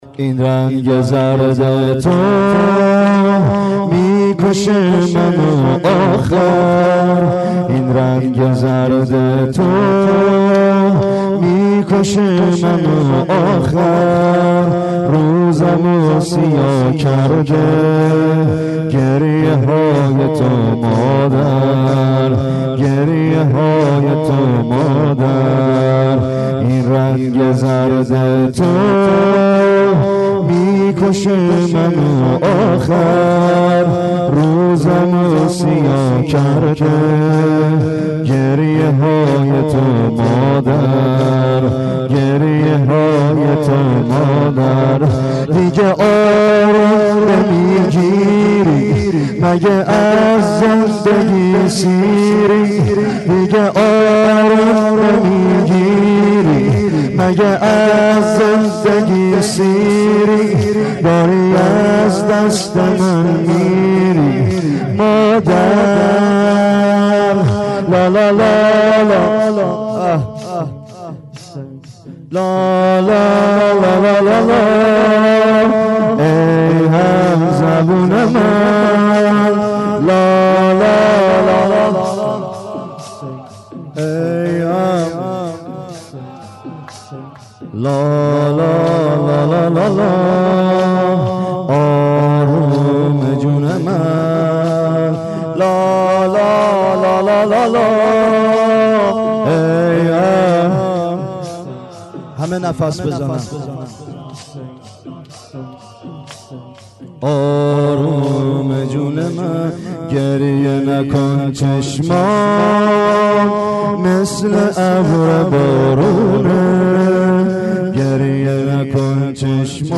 گلچین زمینه های محرم 93
زمینه شب هفتم : این رنگ زرد تو میکشه منو آخر